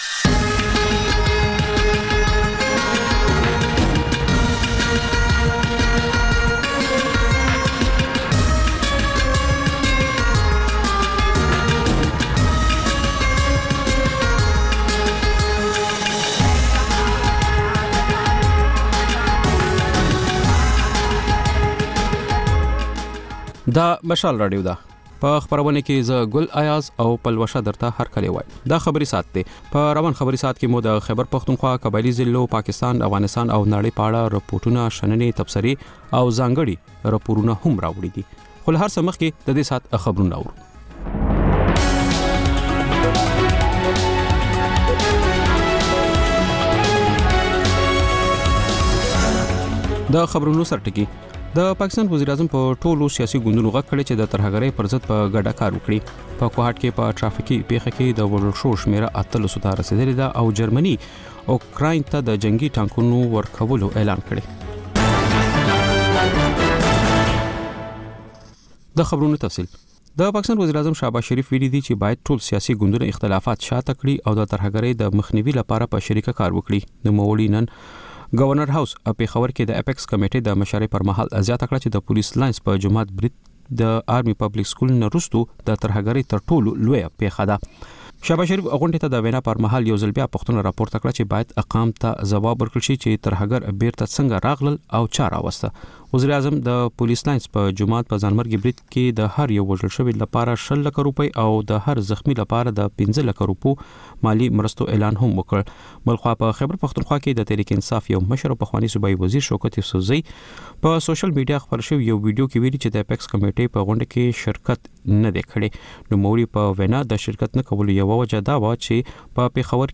دلته د مشال راډیو د سلام پښتونخوا خپرونې تکرار اورئ. په دې خپرونه کې تر خبرونو وروسته رپورټونه خپروو او پکې د سیمې اوسېدونکو د خپلو کلیو او ښارونو تازه او مهم خبرونه راکوي. په خپرونه کې سندرې هم خپرېږي.